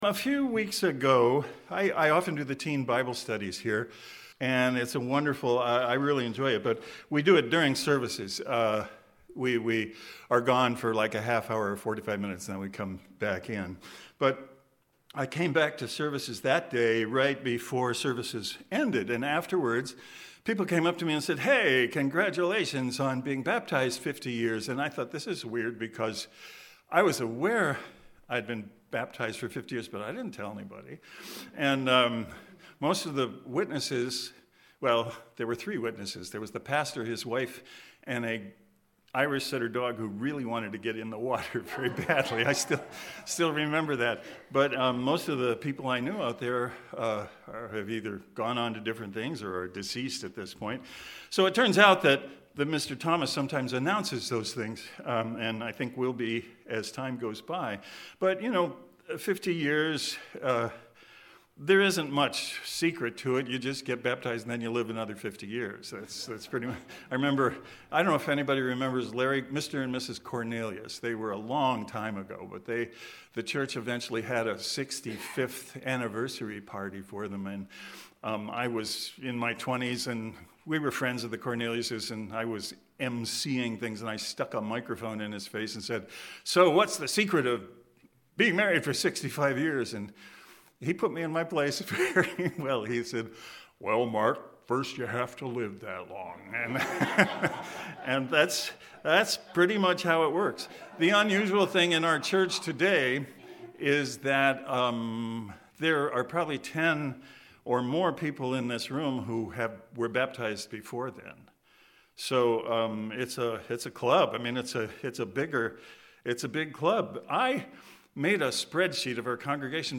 Given in Cleveland, OH